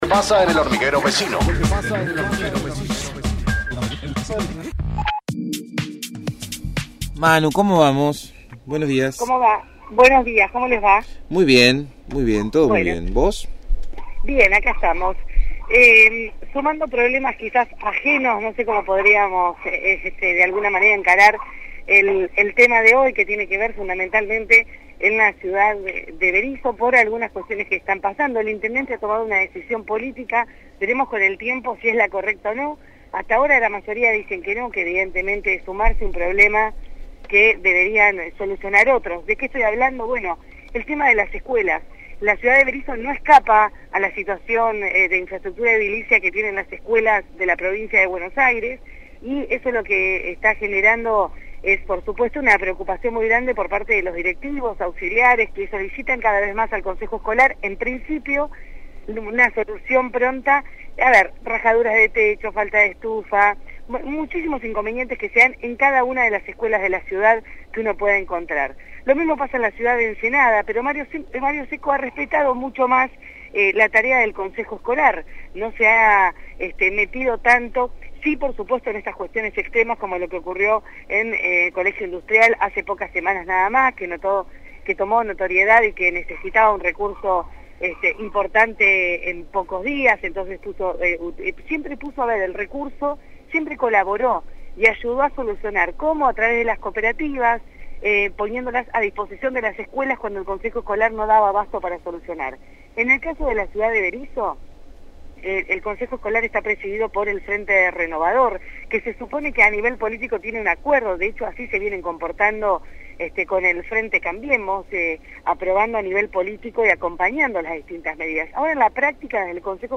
realizó su informe sobre la actualidad en las localidades vecinas. En esta oportunidad, se refirió a la decisión del intendente de Berisso, Jorge Nedela, de recorrer las escuelas en persona, obviando el rol de los consejeros escolares.